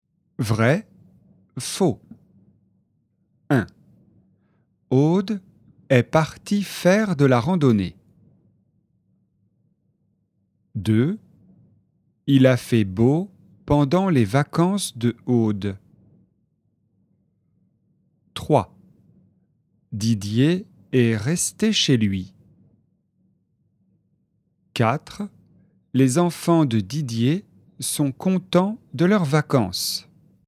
Dialogue FLE et exercice de compréhension, niveau débutant (A1) sur le thème des vacances.